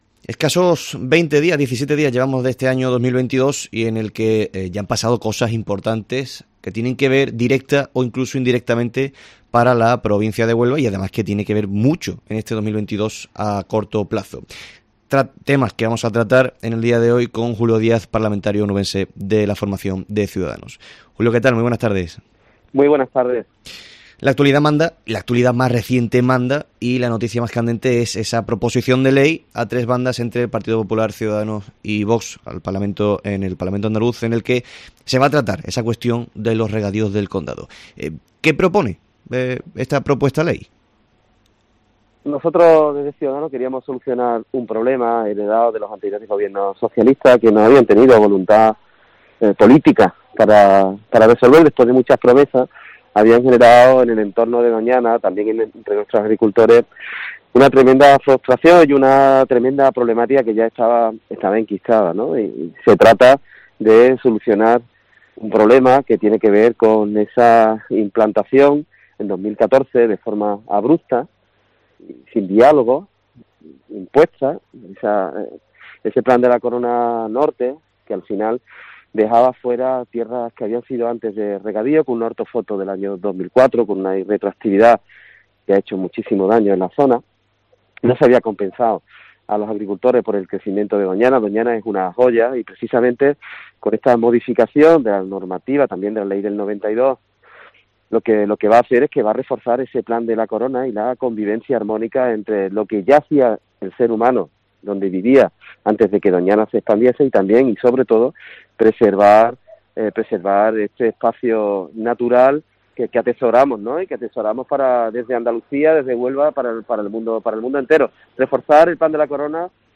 Julio Díaz, parlamentario onubense de Ciudadanos